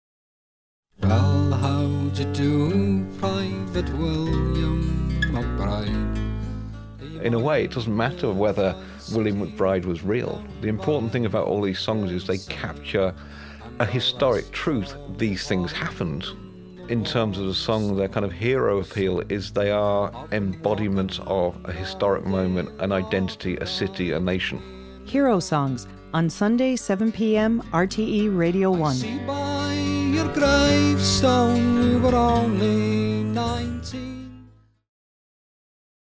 Herosongs: Episode 7 Promo